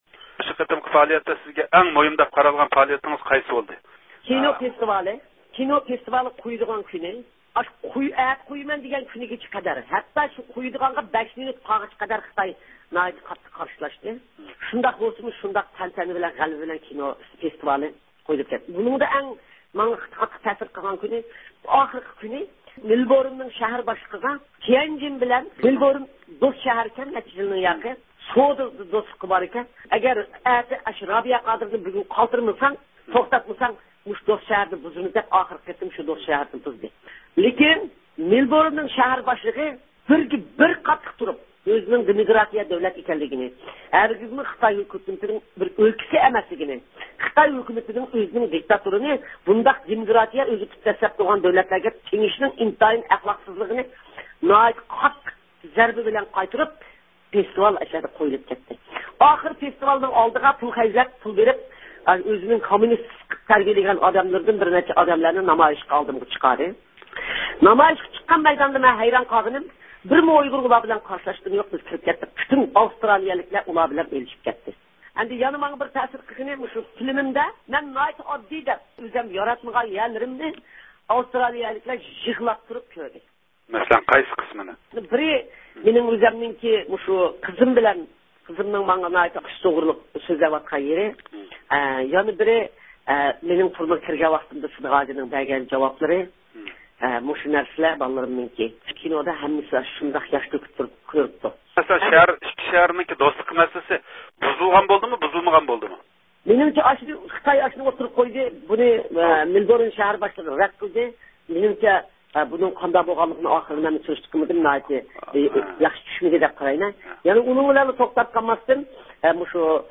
قايتىش سەپىرى ئالدىدا مۇخبىرىمىزنىڭ زىيارىتىنى قوبۇل قىلغان رابىيە قادىر خانىم، 10 كۈنلۈك زىيارىتىنىڭ مۇھىم نۇقتىلىرى ھەققىدە تەسىراتىنى سۆزلەپ بەردى ۋە ۋەتەن ئىچىدىكى خەلققە سالام يوللىدى.